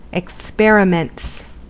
Ääntäminen
Ääntäminen US : IPA : [ɪk.ˈspɛɹ.ɪ.mənts] Tuntematon aksentti: IPA : /ɪk.ˈspɛɹ.ə.mənts/ Haettu sana löytyi näillä lähdekielillä: englanti Käännöksiä ei löytynyt valitulle kohdekielelle.